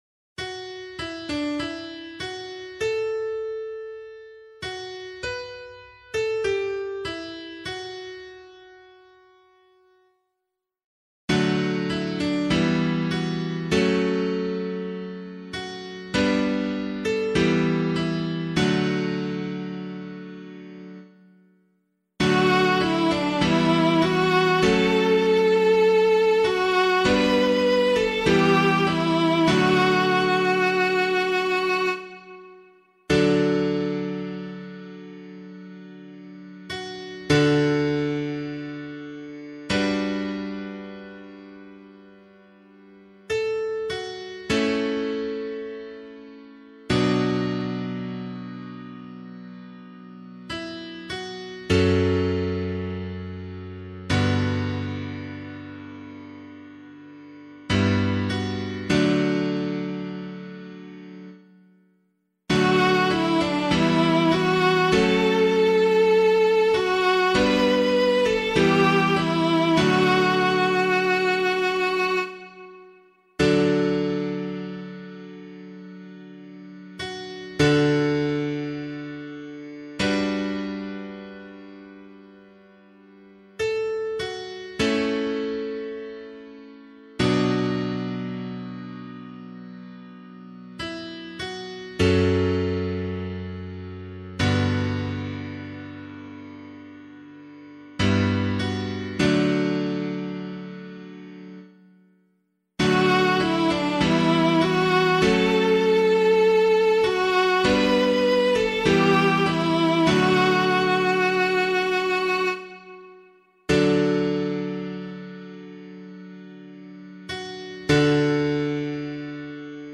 025 Easter 3 Psalm C [APC - LiturgyShare + Meinrad 5] - piano.mp3